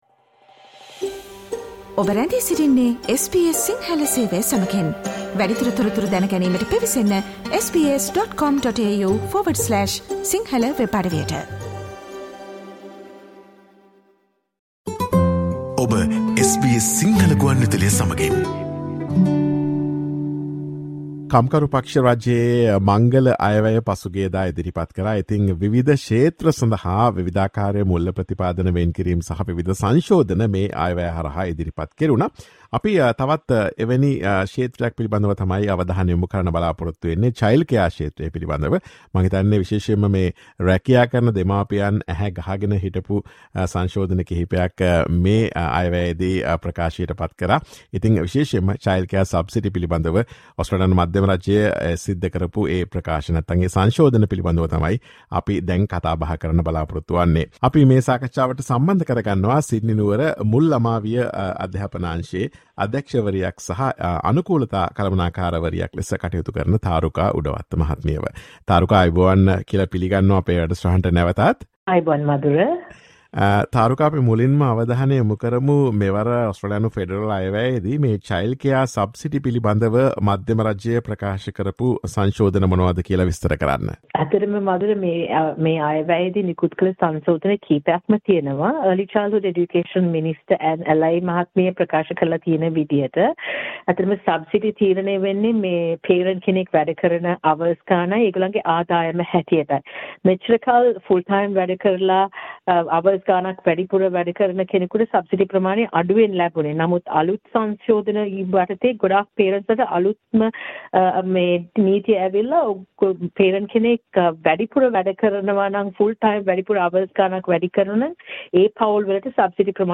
Listen to SBS Sinhala Radio's discussion on the Childcare Subsidy amendments announced in the latest Australian Federal Budget.